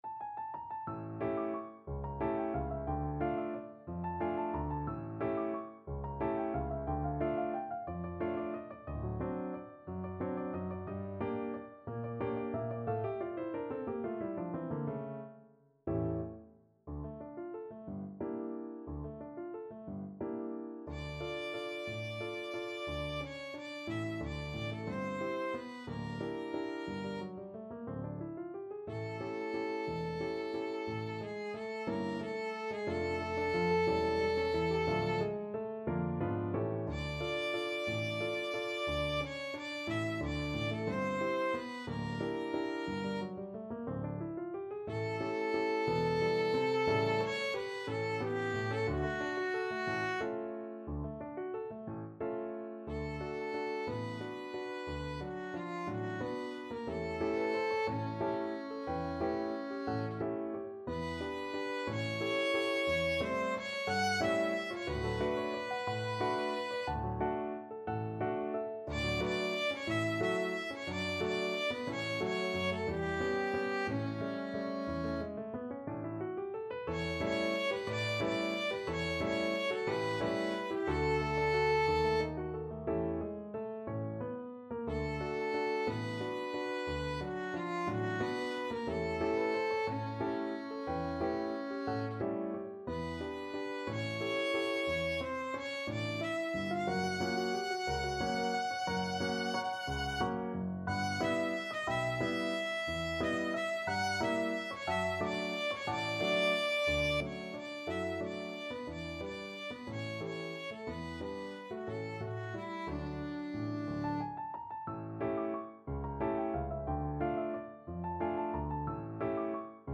3/4 (View more 3/4 Music)
Allegro movido =180 (View more music marked Allegro)
Db5-F#6
Classical (View more Classical Violin Music)
world (View more world Violin Music)
Mexican